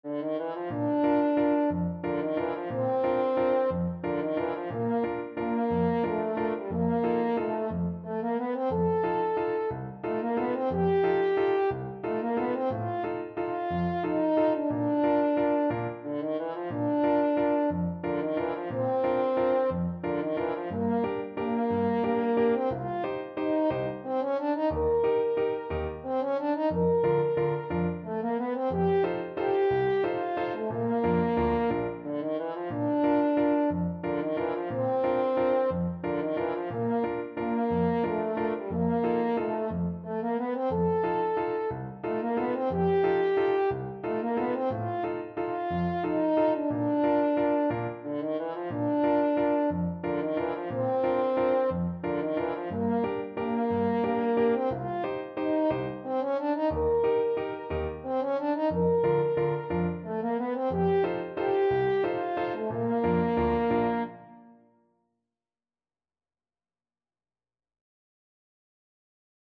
Free Sheet music for French Horn
French Horn
3/4 (View more 3/4 Music)
Bb major (Sounding Pitch) F major (French Horn in F) (View more Bb major Music for French Horn )
One in a bar .=c.60